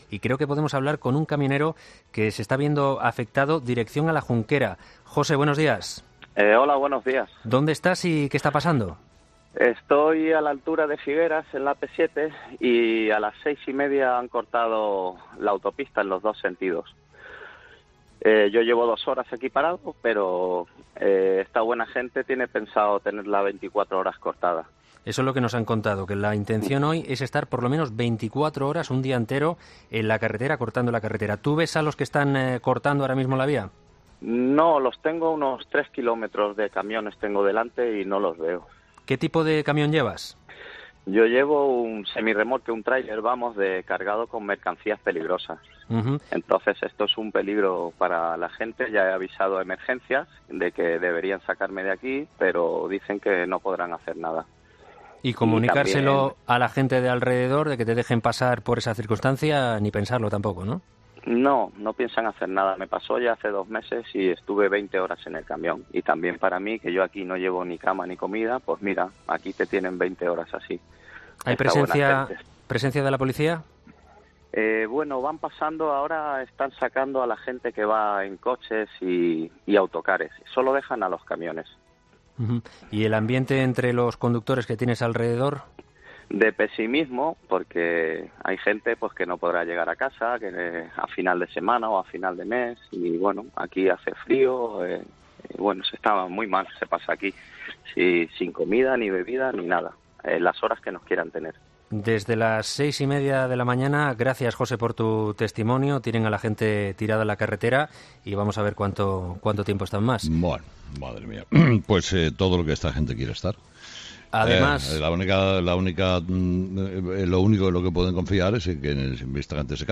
Con Carlos Herrera